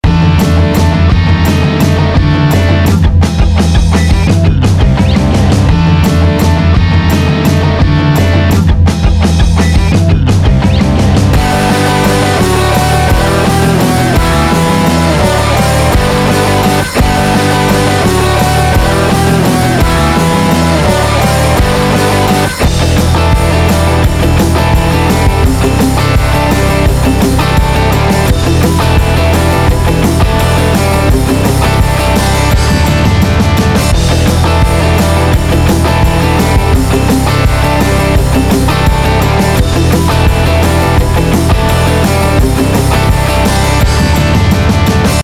Ich wechsle nun zum Mid/Side-Betrieb, nehme den Air-Modus für die Mittelsignalbearbeitung heraus und mache das Gleiche mit dem Big-Modus für das tiefe Band.
Es ist ein Kinderspiel, die Bassdrum herauszustellen, ohne dass dabei die Becken unerwünschte Pegelschwankungen aufweisen würden, wie das bei Singe-Band-Kompressoren in solchen Fällen regelmässig passiert.